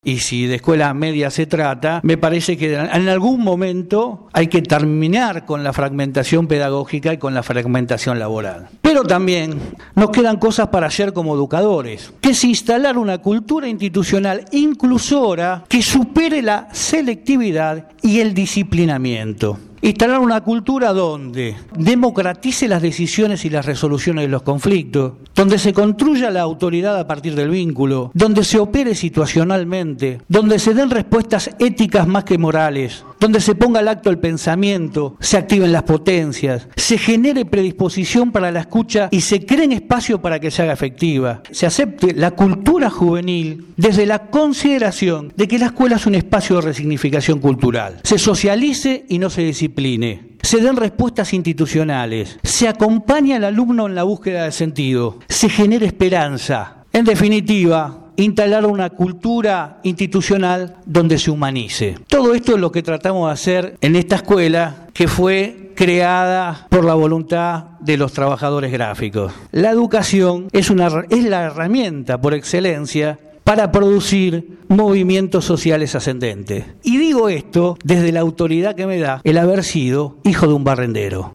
Organizado por Radio Gráfica y el programa «Cambio y Futuro», el último 19 de Mayo se realizó en la instalaciones de la radio la charla debate «Educación en el Bicentenario«.
Ante un centenar de presentes, la expectativa era mucha porque el panel de invitados así lo proponía: